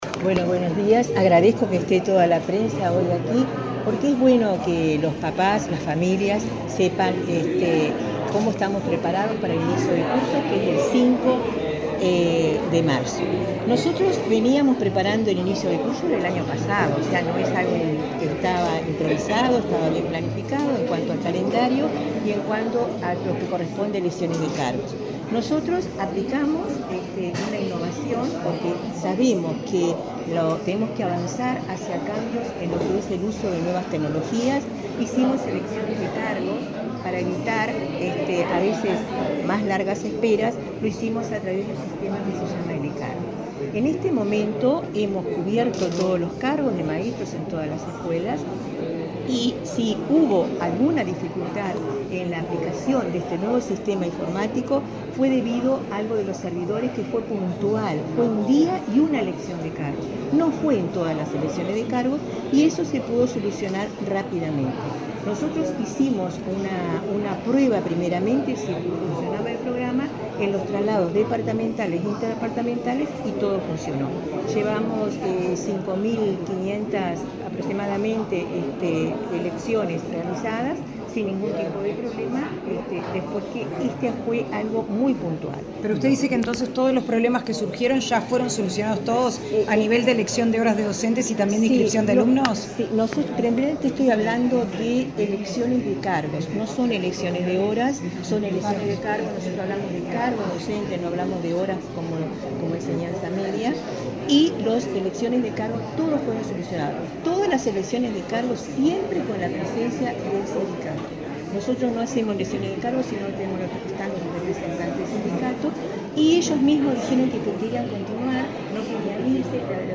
Declaraciones de la directora general de Educación Inicial y Primaria, Olga de las Heras
Este lunes 24 en Montevideo, la directora general de Educación Inicial y Primaria, Olga de las Heras, dialogó con la prensa del inicio del año lectivo